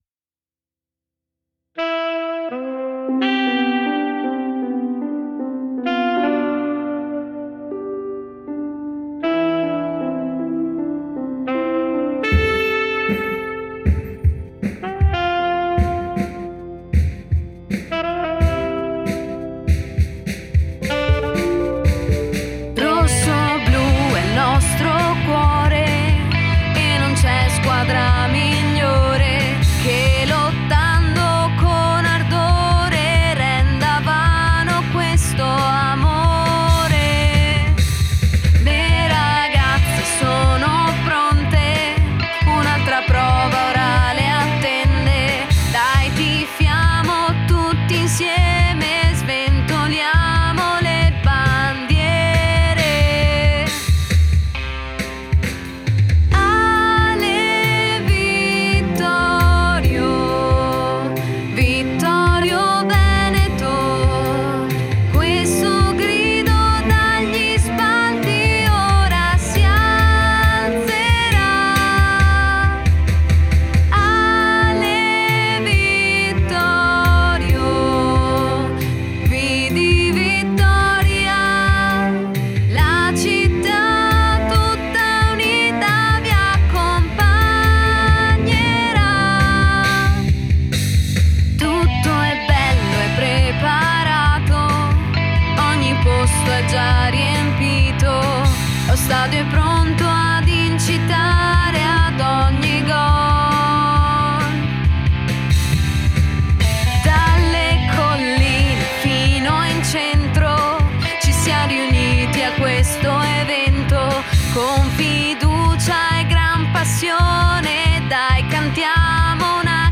this is the title of the new official anthem rossoblu.